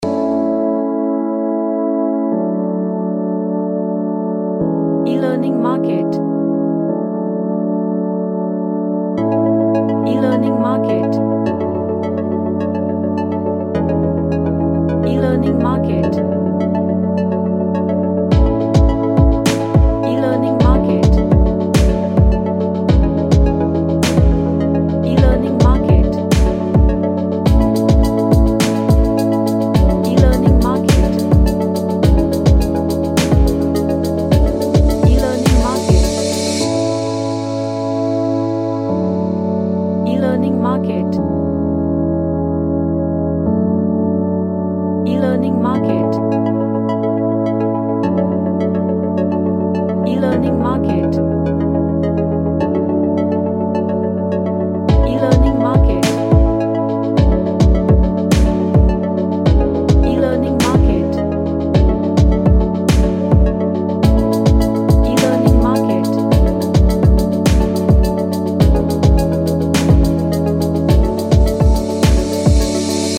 An Electro-pop track with arp style melody
Happy